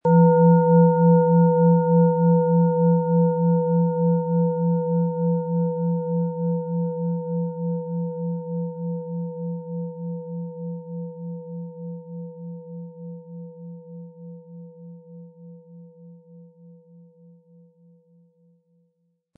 Planetenton
Platonisches Jahr
• Einsatzbereich: Über dem Kopf sehr intensiv spürbar. Ein unpersönlicher Ton.
Sie möchten den schönen Klang dieser Schale hören? Spielen Sie bitte den Originalklang im Sound-Player - Jetzt reinhören ab.
Wohltuende Klänge bekommen Sie aus dieser Schale, wenn Sie sie mit dem kostenlosen Klöppel sanft anspielen.
SchalenformBihar
MaterialBronze